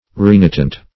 Renitent \Re*ni"tent\ (-tent), a. [L. renitens, -entis, p. pr.